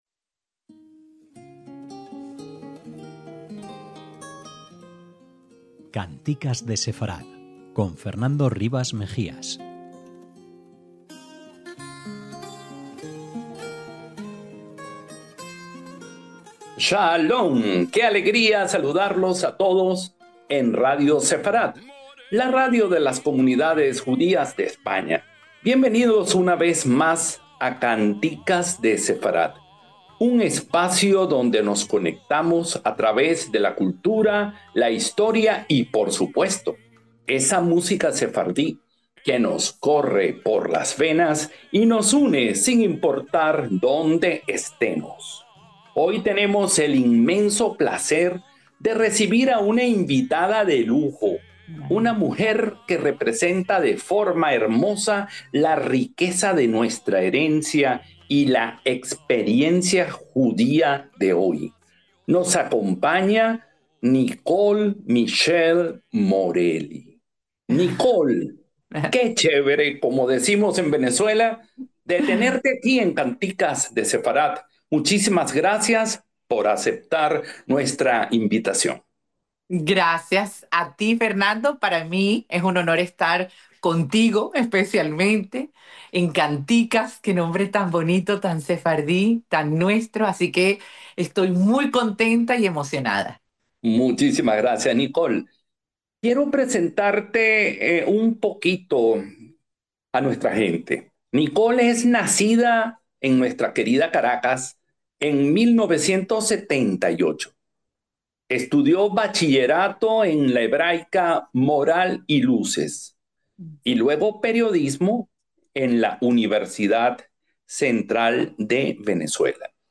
al ritmo de esas kantikas que nos acarician el alma.